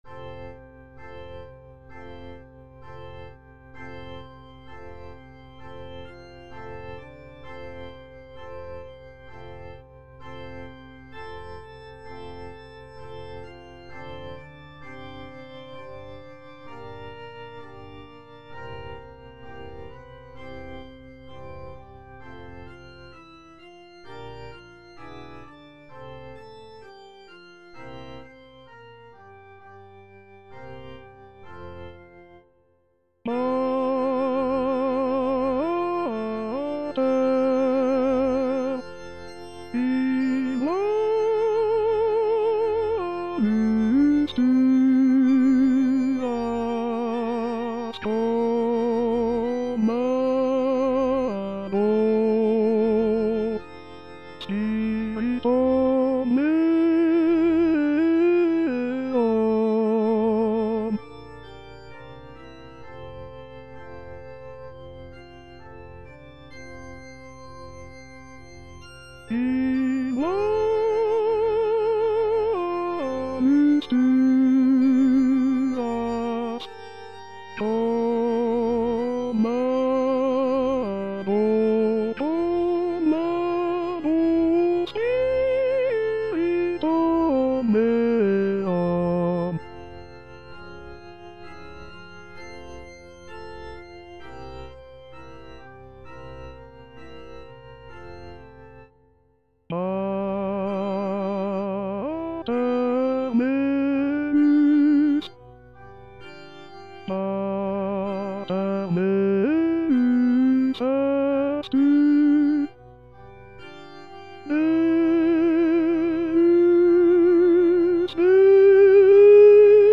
Parole 7: Pater, in manus tuas        Prononciation gallicane (à la française)
Tutti